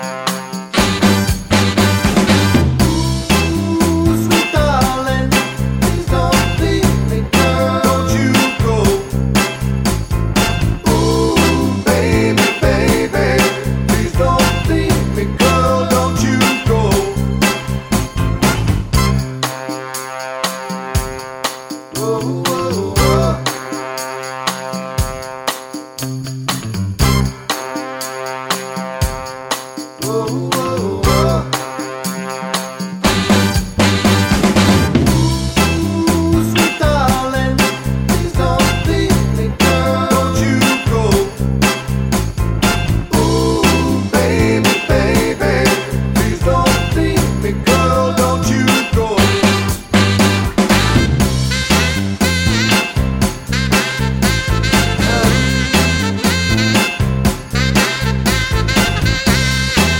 no Backing Vocals Soul / Motown 2:34 Buy £1.50